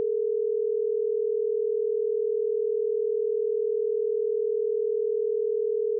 Example 6: 40Hz Binaural Beat
Two tones presented binaurally (420Hz on the left, 460Hz on the right)